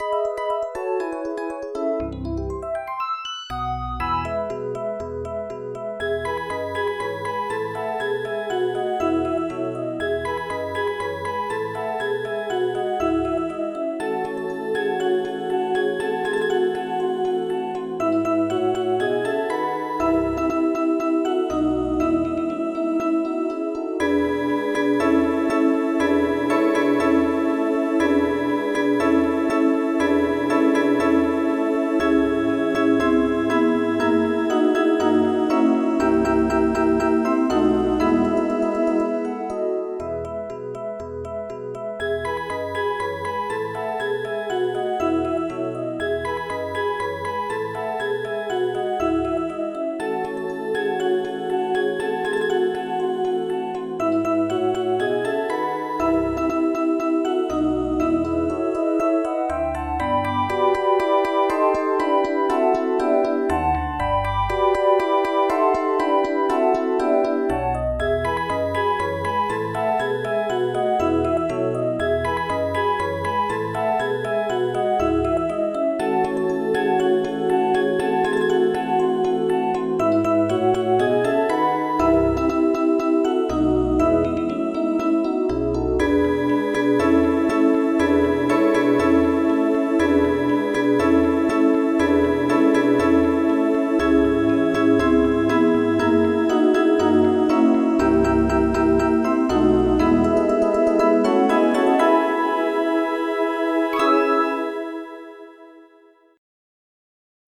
Georgian MID Songs for Children